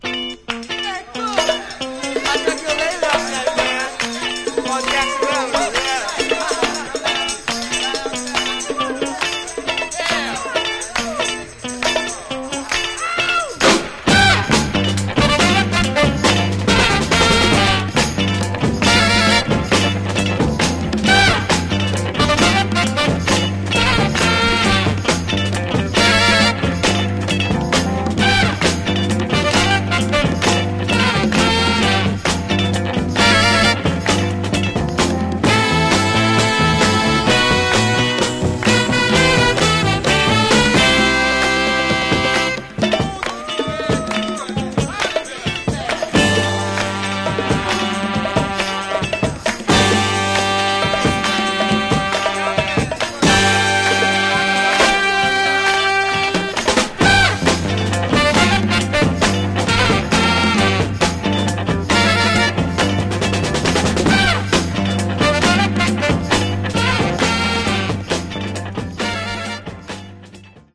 Genre: Soul Instrumentals